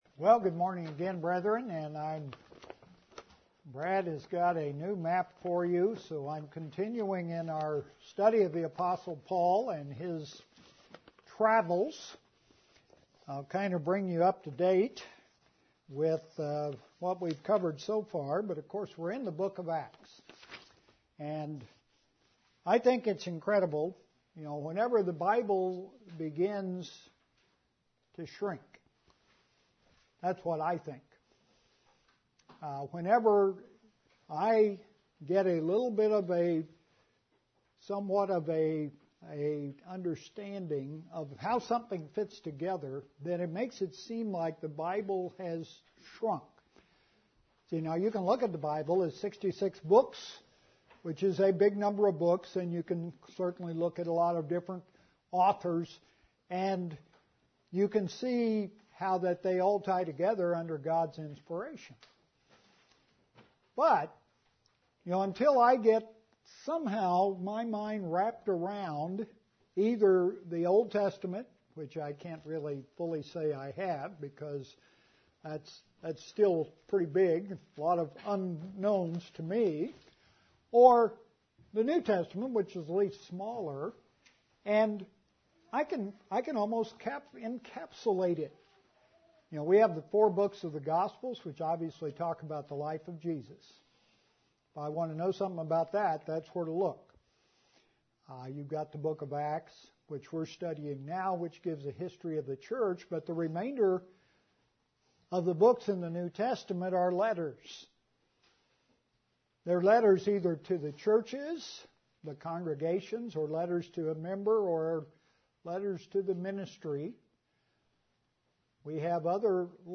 The next sermon in the series on the Book of Acts and the work of the Apostle Paul.